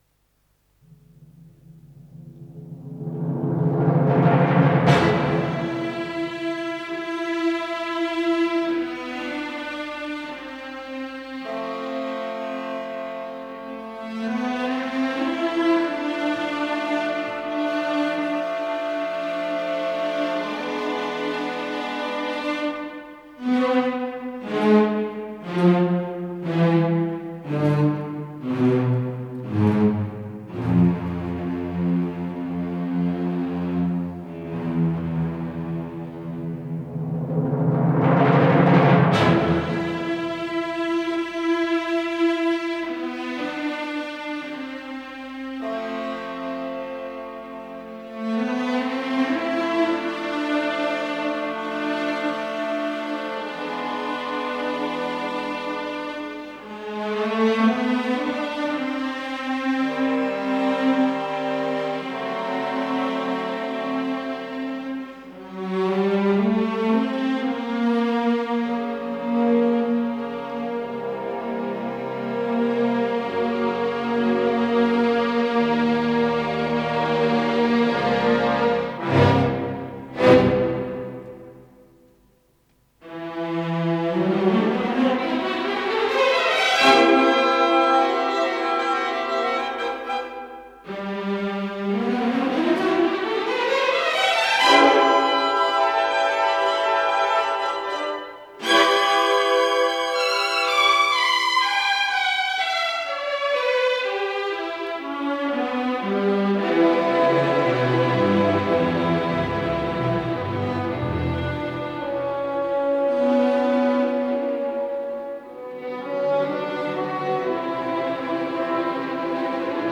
Исполнитель: Государственный симфонический оркестр СССР
Название передачи Гамлет Подзаголовок Увертюра - фантазия по Уильям Шекспир. Соч. 67, фа минор Код ПКС-011687 Фонд Без фонда (ГДРЗ) Редакция Музыкальная Общее звучание 00:16:46 Дата добавления 13.10.2024 Прослушать